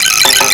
WOOD.WAV